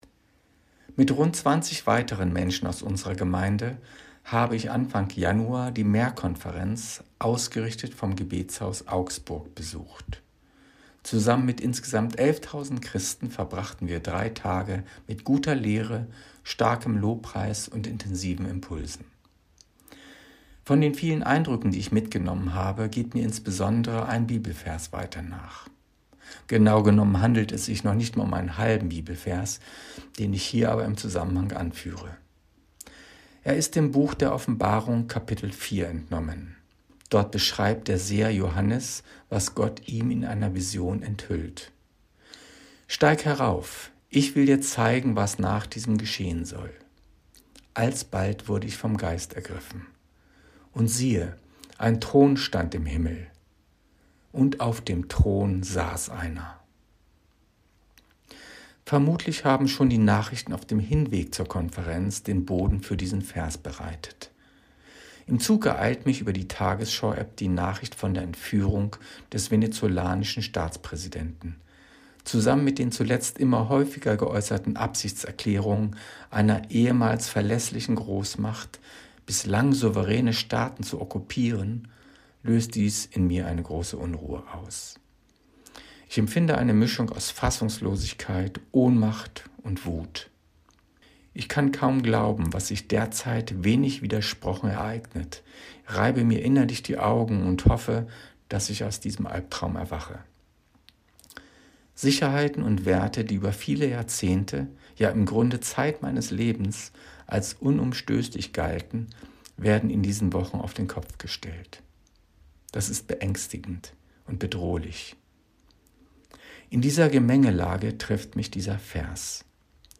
Andacht